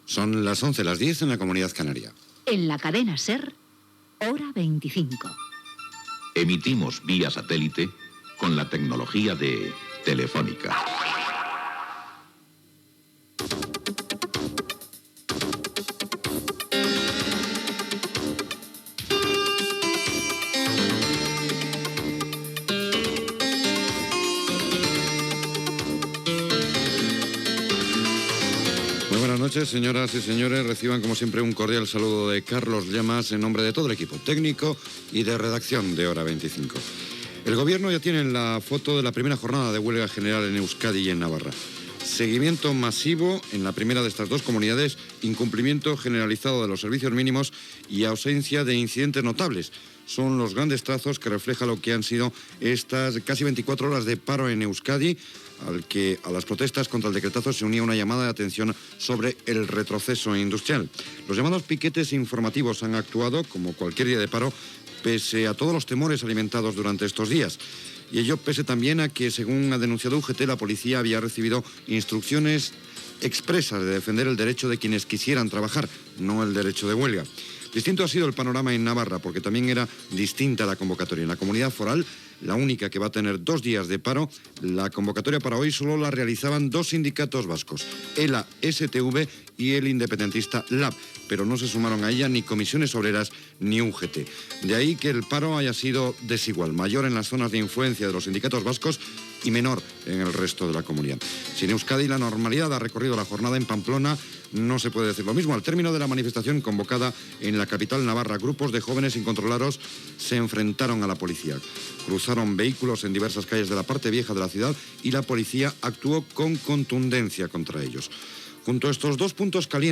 Identificació del programa, salutació, informació de la vaga general a Euskadi, Navarra i Huelva, sumari informatiu, publicitat i indicatiu
Informatiu